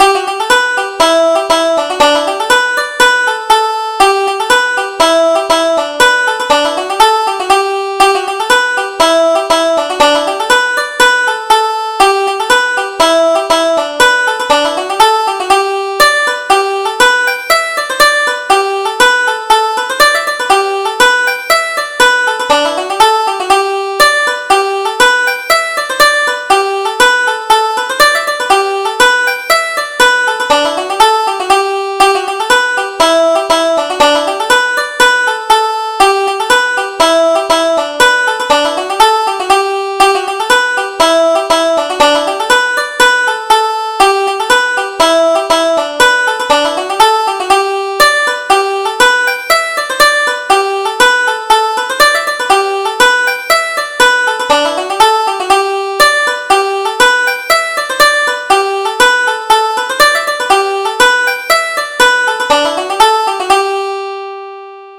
Irish Traditional Polkas